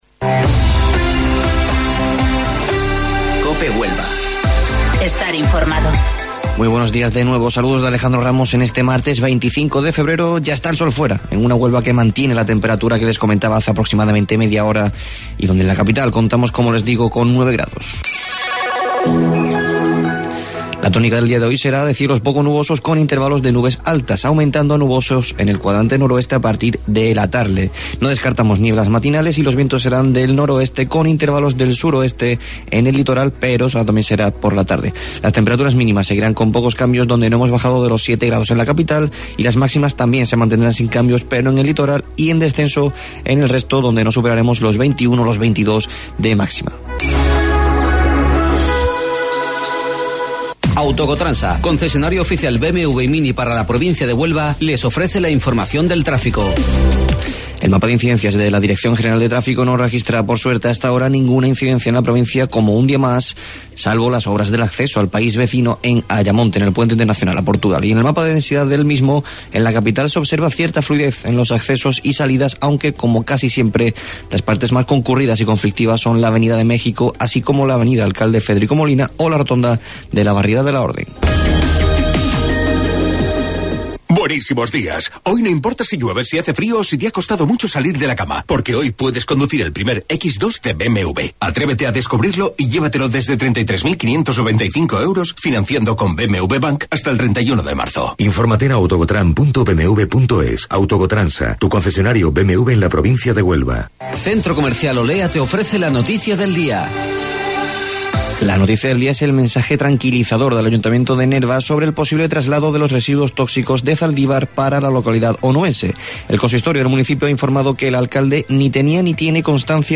AUDIO: Informativo Local 08:25 del 25 Febrero